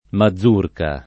vai all'elenco alfabetico delle voci ingrandisci il carattere 100% rimpicciolisci il carattere stampa invia tramite posta elettronica codividi su Facebook mazurca [ ma zz2 rka ] o mazurka [ id.; pol. ma @2 rka ] s. f.